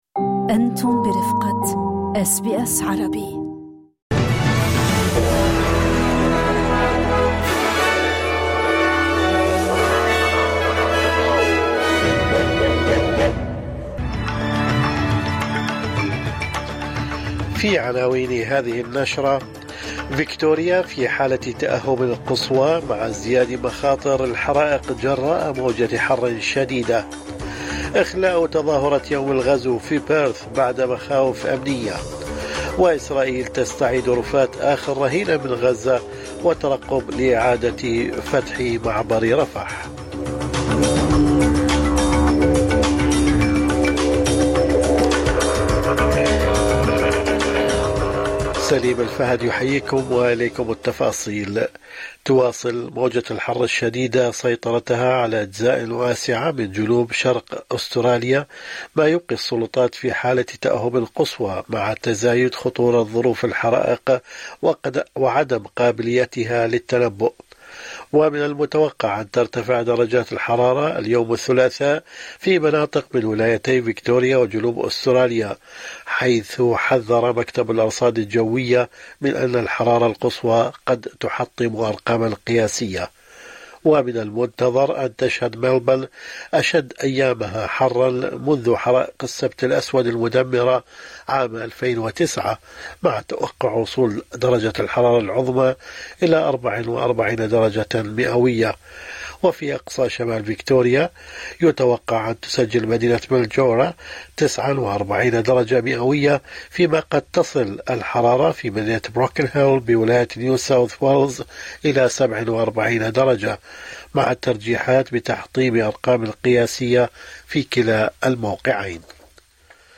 نشرة أخبار الصباح 27/1/2026